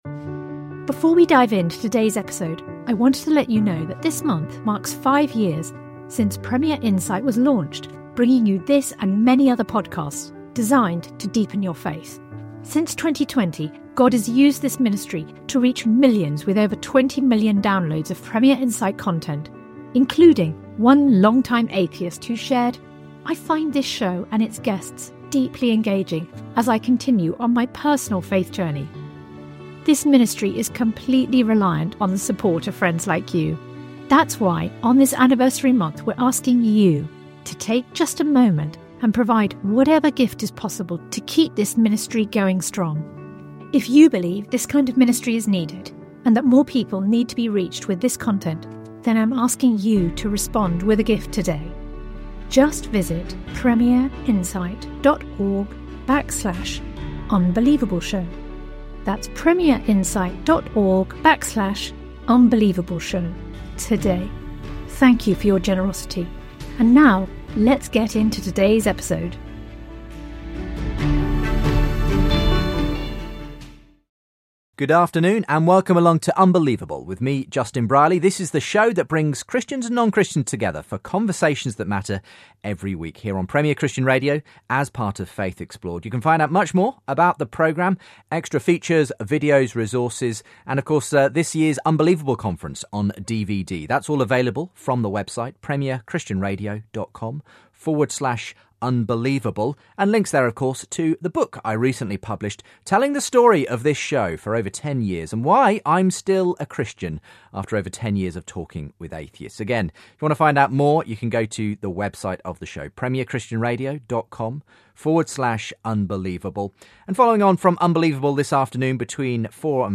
In this show he dialogues with young atheist Alex O Connor who runs the popular Cosmic Skeptic YouTube channel.